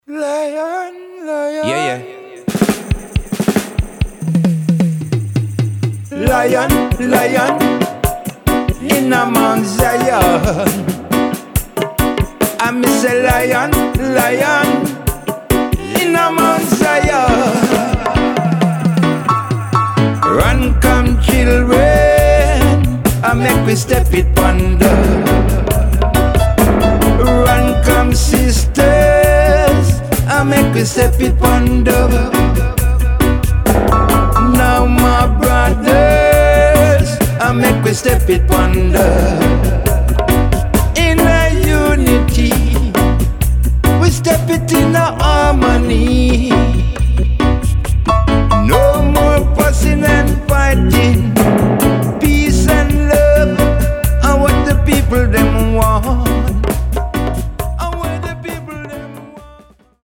12″ Showcase with Vocals Dubs & Versions.
modern roots steppers
All recorded, mixed & mastered